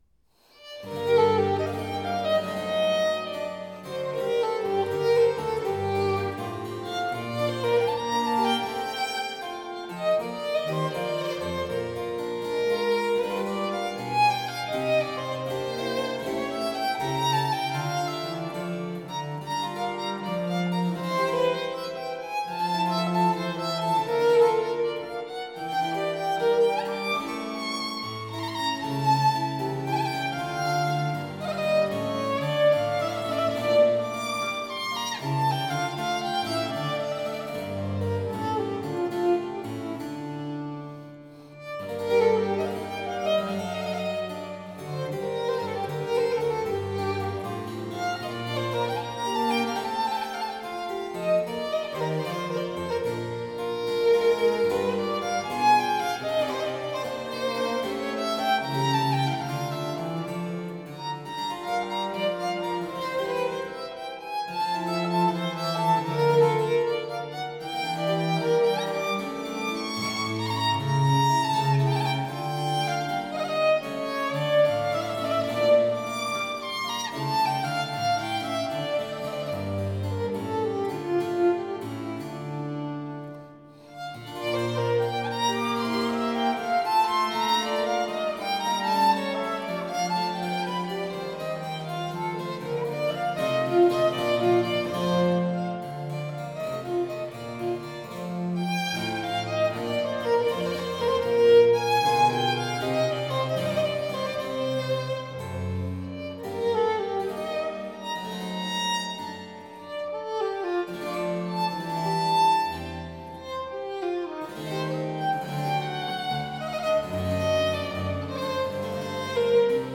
Sonate op. I & op. II per violino e basso
cello
cembalo